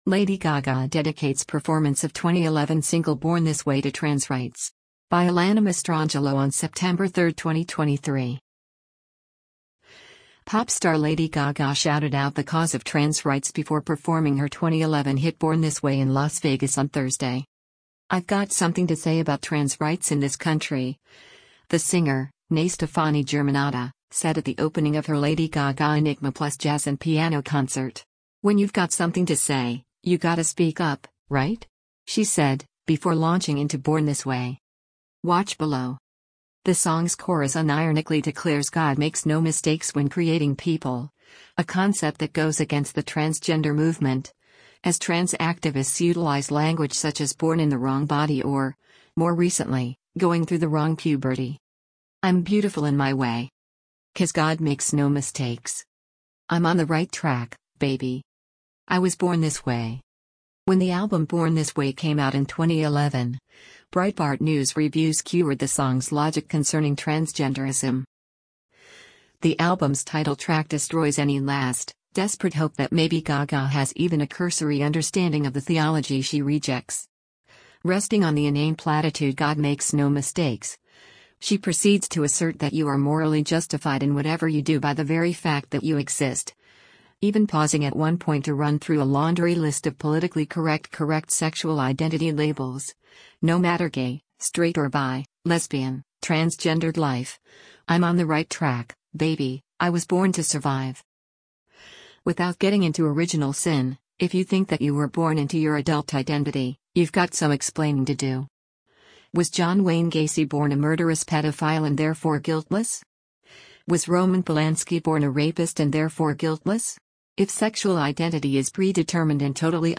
acoustic rendition